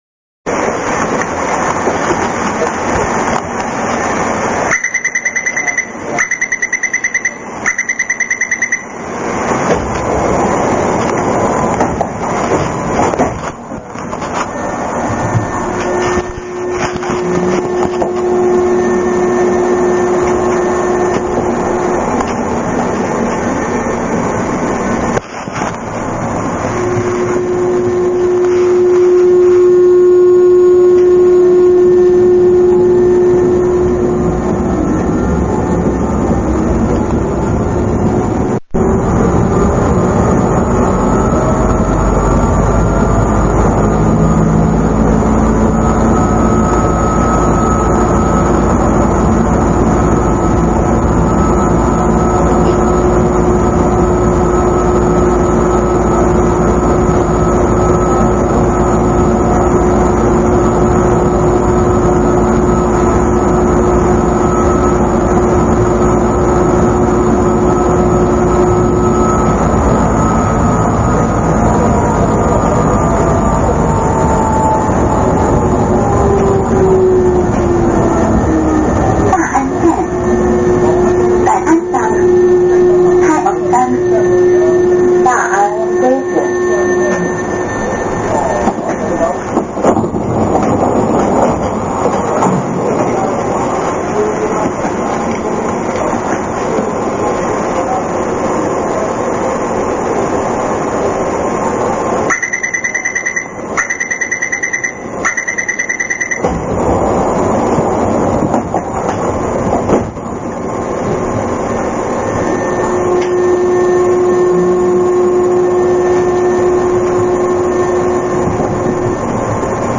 Taiwan MRT